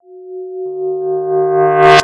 标签： 108 bpm Moombahton Loops Fx Loops 174.95 KB wav Key : Unknown
声道单声道